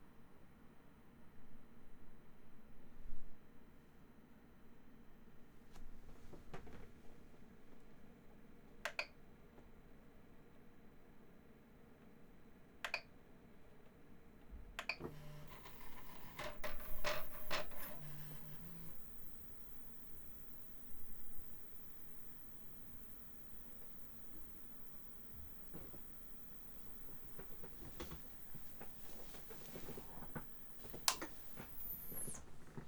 High Pitch static noise
Recently my printer has been making a wierd high pitch noise from the Einsy board whenever the motors have been used, it didn't do this before, and I've tried to look for reasons or solutions but haven't been able to find any, I would greatly appreciate some help so I don't get tinnitus from my printer.
When I turn on the printer there is no noise, but as soon as I do something that makes the motors move, like home it in this clip, it turns into high pitch static noise after its done, and it makes a weird tune down sound when turned off.
No, the noise is 100% coming from the box with the Einsy in, I've tried putting my ear up and around to listen to the motors also, but its definitely the board.
c338a00a-prusa-noise.mp3